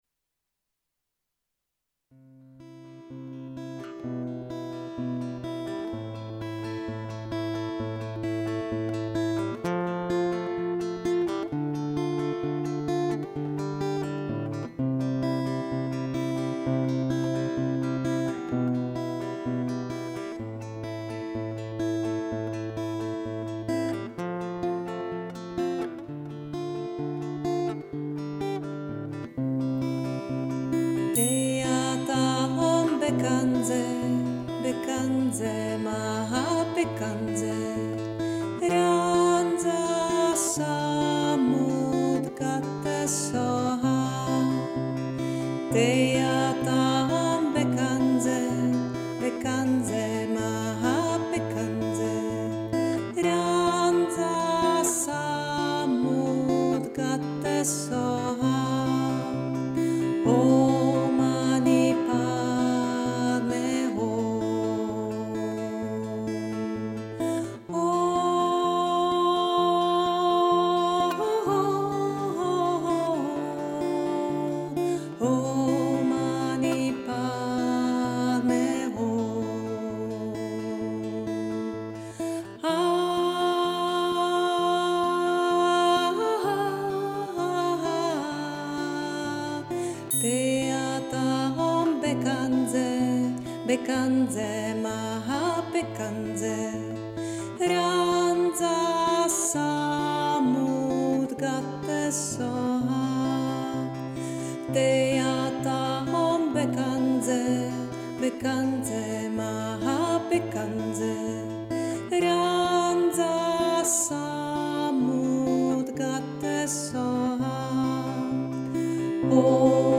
Mantra Buddha Medicine ke stažení Mantra Buddha Medicine ZDE klikněte ke stažení MP3 nahrávky Podle buddhistické tradice mantra očišťuje naši mysl i tělo od tzv. tří základních jedů - nevědomosti, připoutanosti, agrese a napomáhá otevírat srdce.